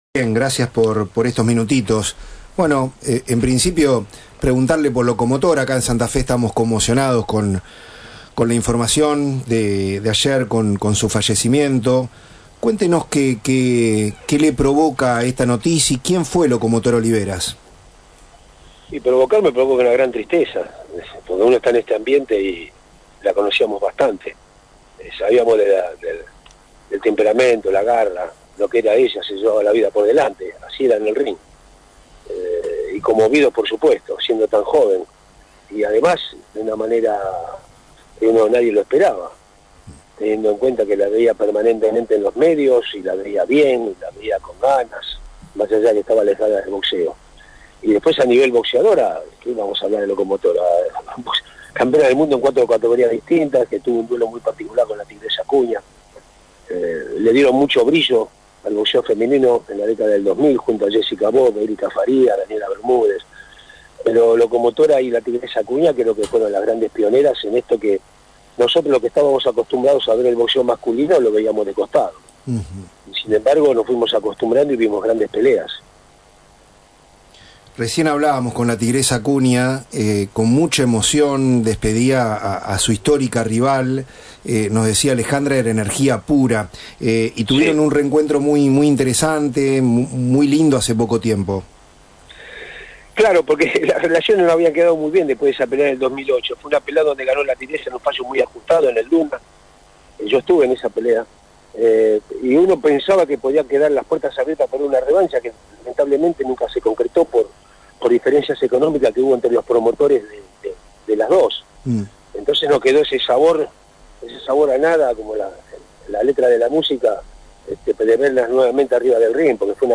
En una entrevista marcada por la emoción y el respeto, Walter Nelson despidió a Alejandra “Locomotora” Oliveras, a quien definió como una referente del boxeo argentino y una mujer con una fuerza arrolladora.
En diálogo exclusivo con La Nueva Nueve, el reconocido periodista deportivo expresó su pesar ante la noticia del fallecimiento de la múltiple campeona mundial.